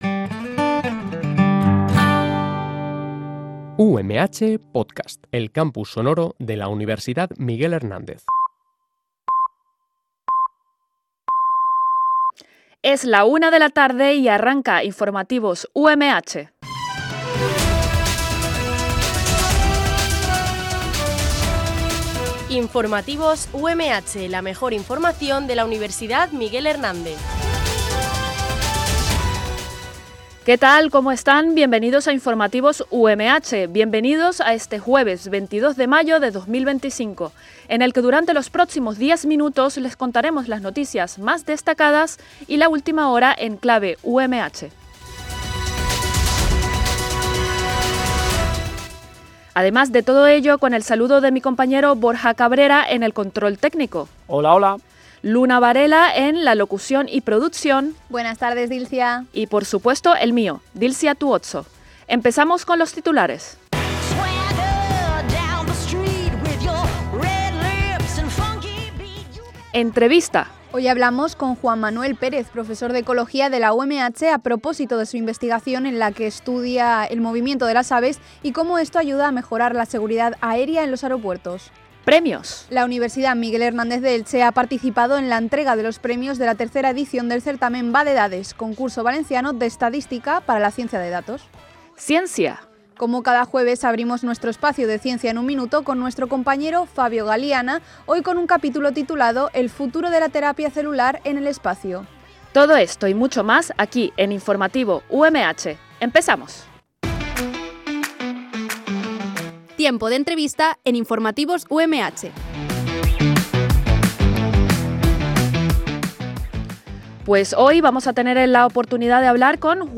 Este programa de noticias se emite de lunes a viernes